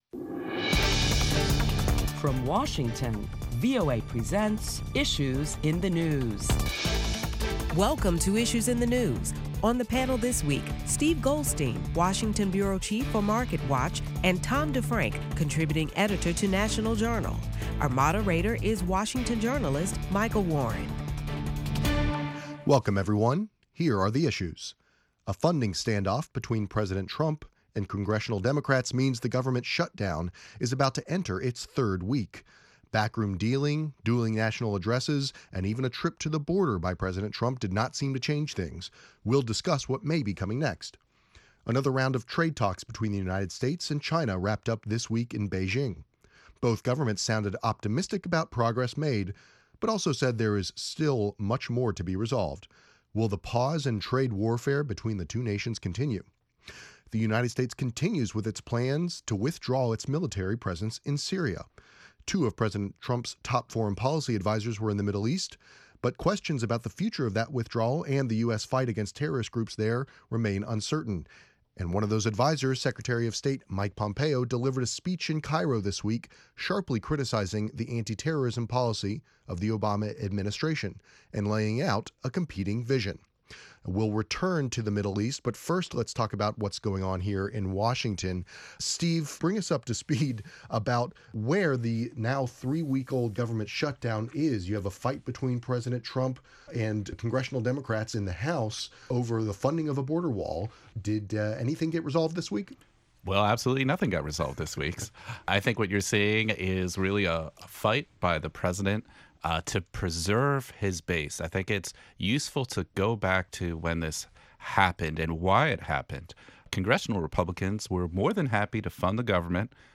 Listen to prominent Washington correspondents discuss the week's top stories including the politics of the continuing shutdown, and the latest on the U.S. withdrawal from Syria.